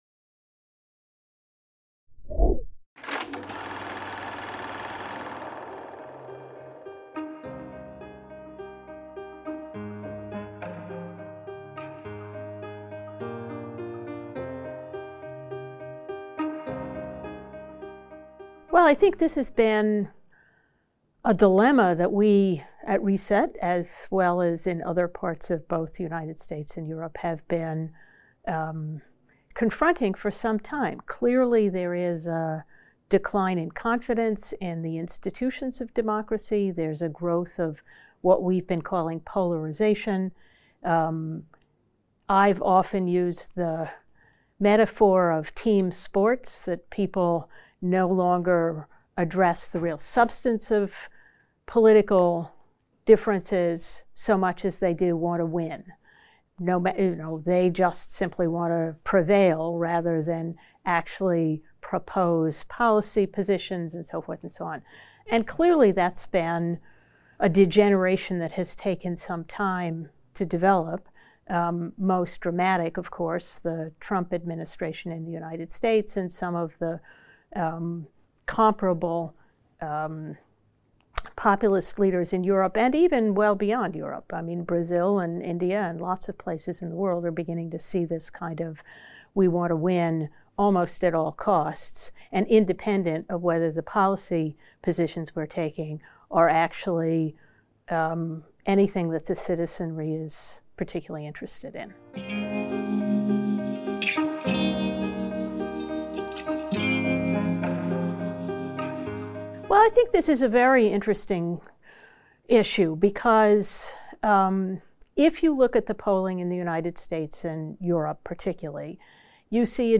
Favoris Ajouter à une playlist Entretien